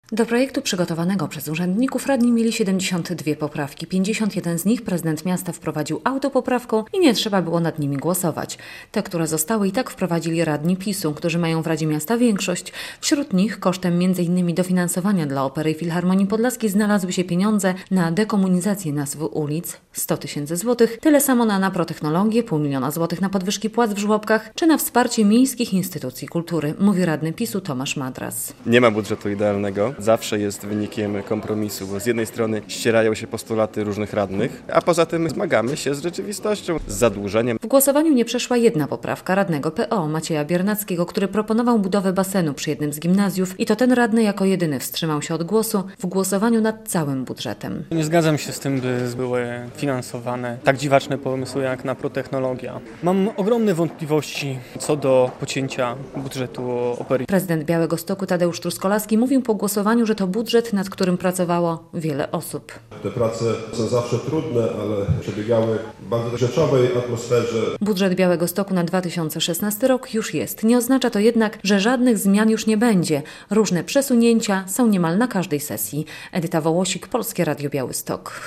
Jest już budżet Białegostoku na 2016 rok - relacja